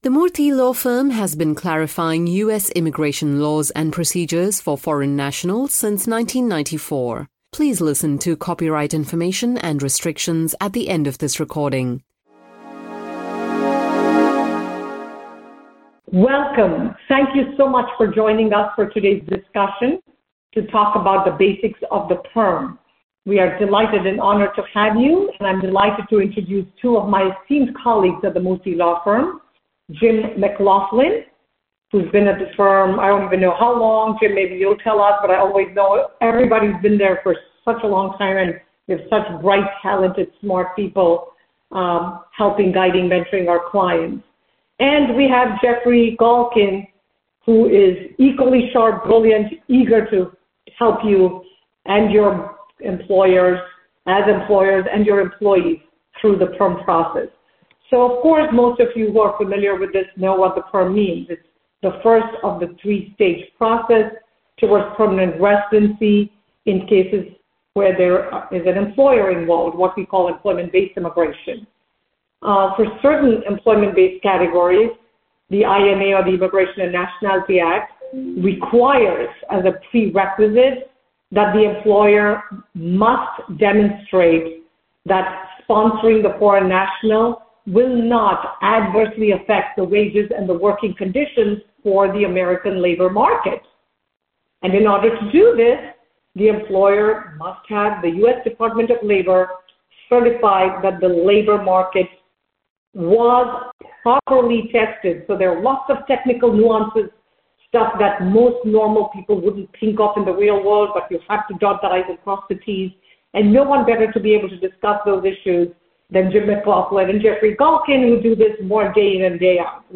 A basic overview of the PERM based green card process is discussed by Murthy Law Firm attorneys in this MurthyAudio production from 02.Jul.2025, recommended for employers and their representatives. Included in this topic are the Labor Certification (ETA 9089 form), I-140 immigrant petition, and I-485 application to adjust status.